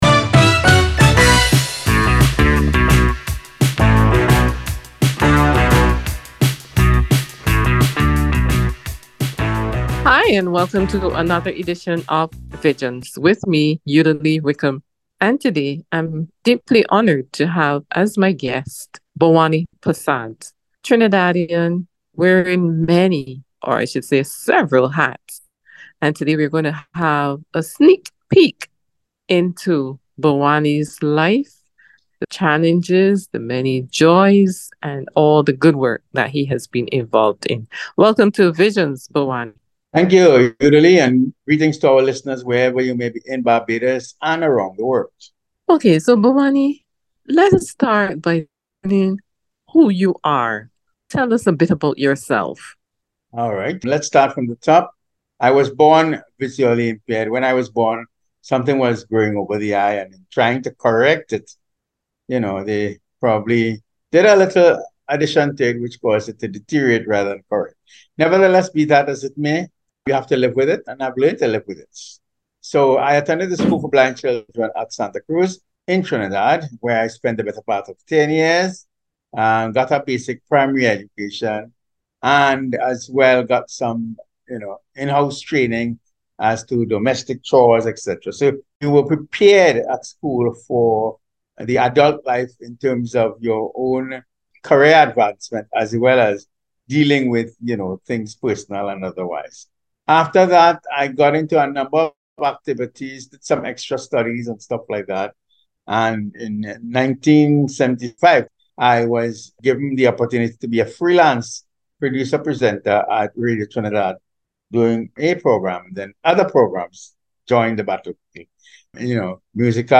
Visions Aug 20th 2023 - Interview with